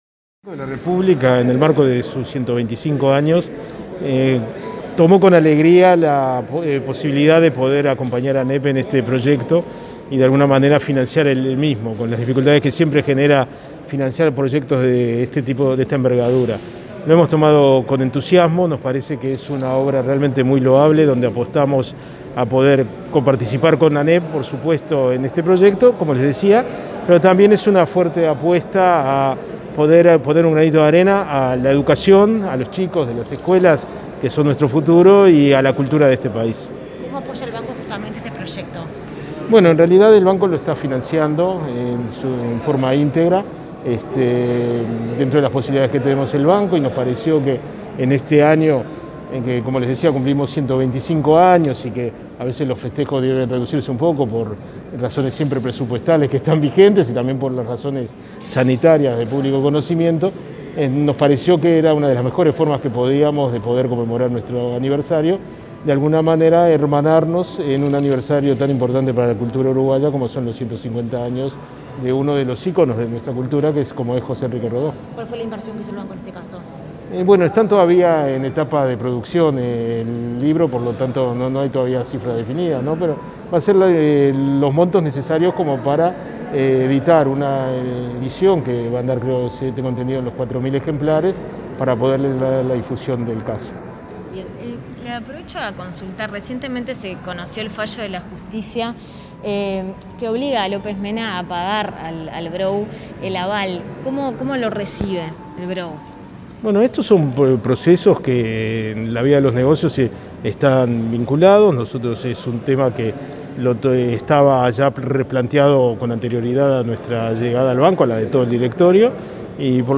Declaraciones a la prensa del director del BROU, Max Sapolinski
Declaraciones a la prensa del director del BROU, Max Sapolinski 03/08/2021 Compartir Facebook Twitter Copiar enlace WhatsApp LinkedIn Finalizada la presentación del concurso de cuentos Dibujando a Rodó, realizada este martes 3 en la sede del Codicen, Sapolinski bridó declaraciones a la prensa.